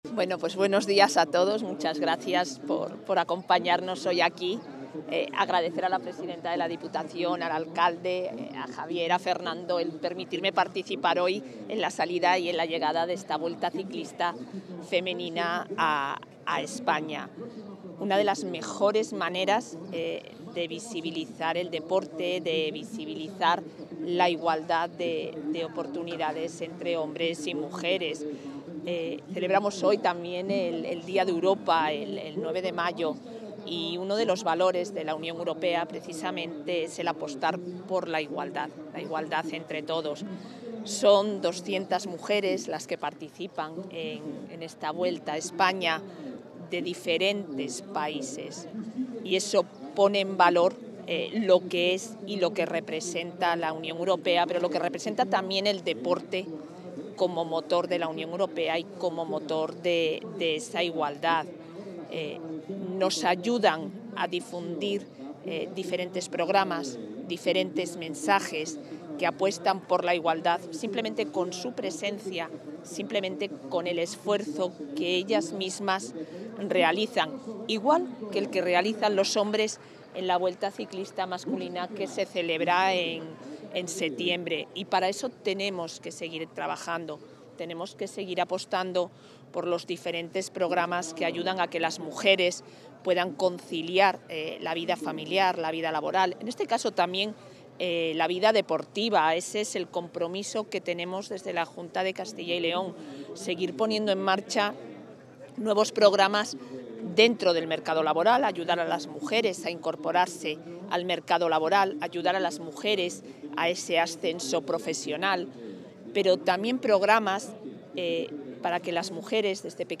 Declaraciones de la vicepresidenta.